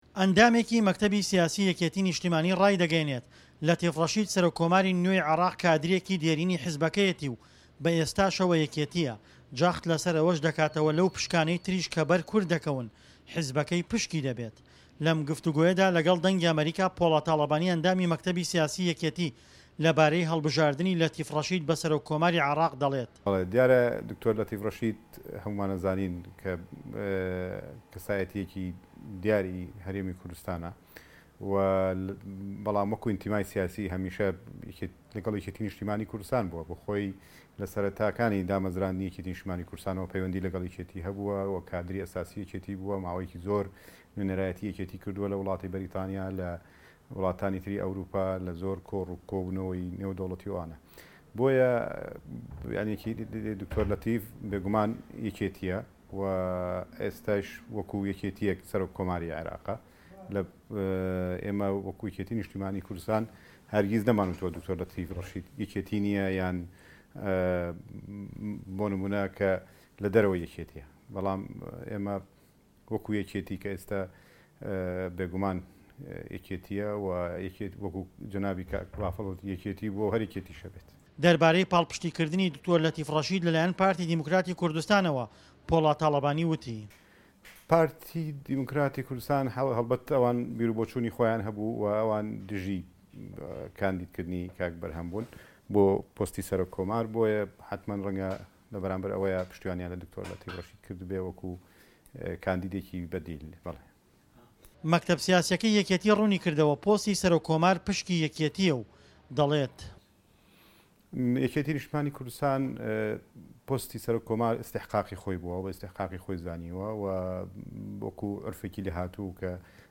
عێراق - گفتوگۆکان